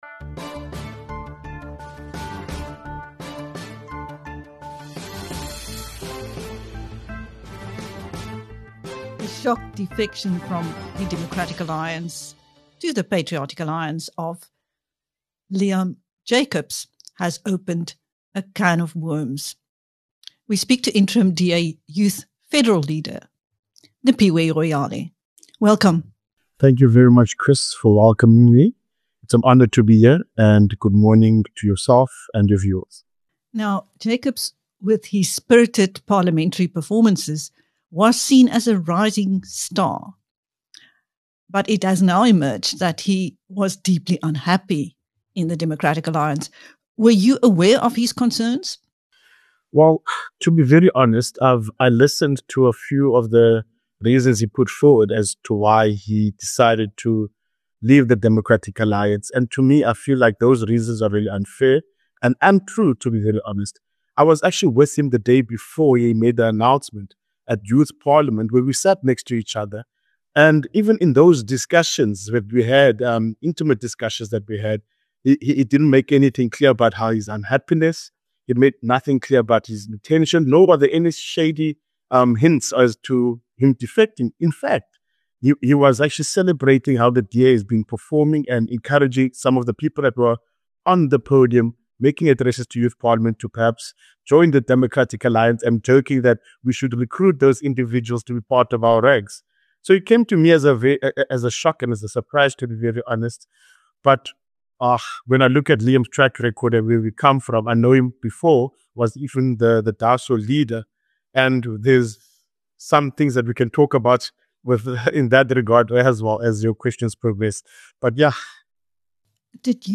In this interview with BizNews